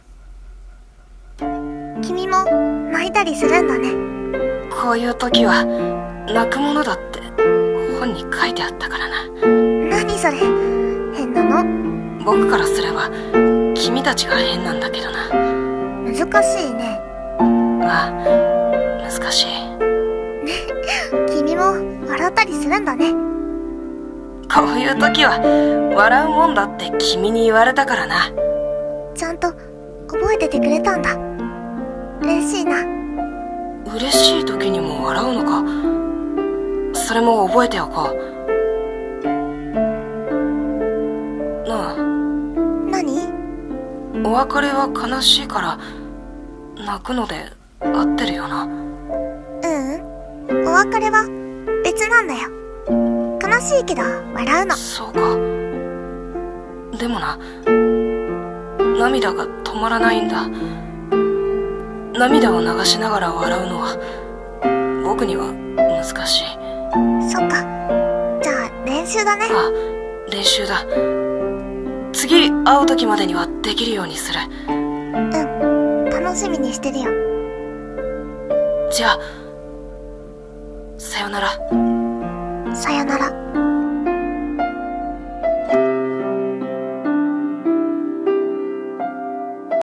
声劇台本】感情のない少年